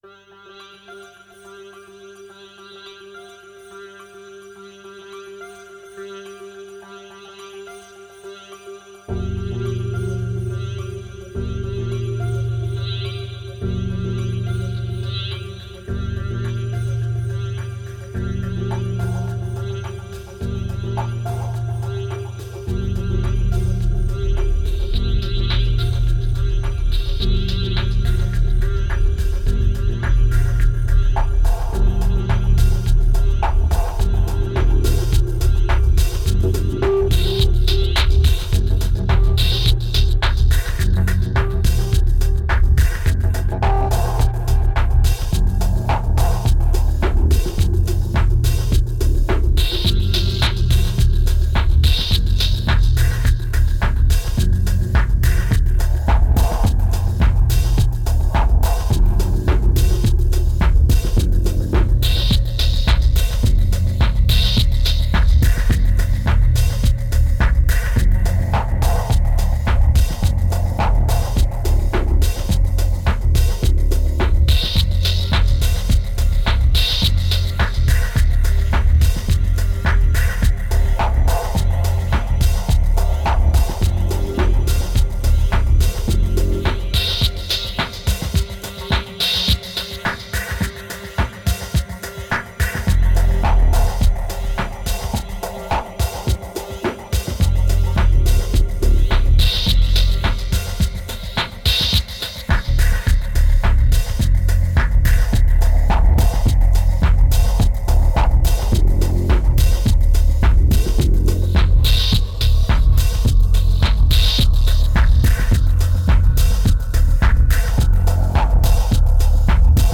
1969📈 - -2%🤔 - 106BPM🔊 - 2010-11-18📅 - -425🌟